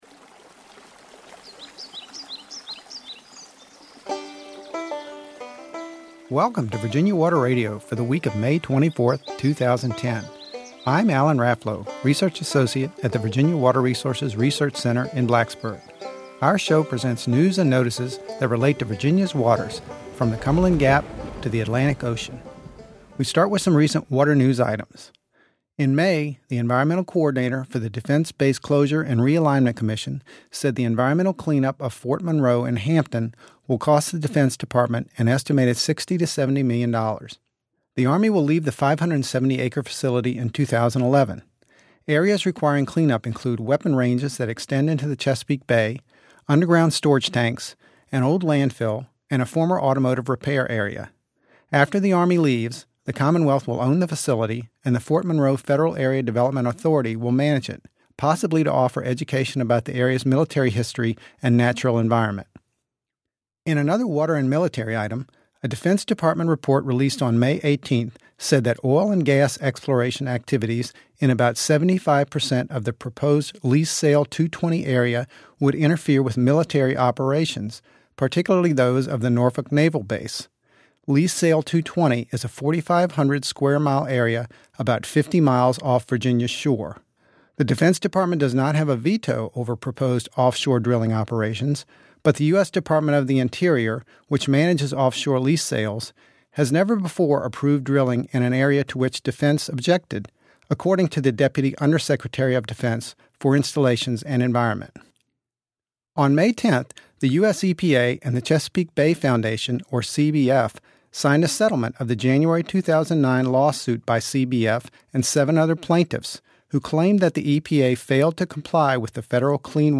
WATER SOUNDS AND MUSIC
This week we featured a new mystery sound: The Atlantic Croaker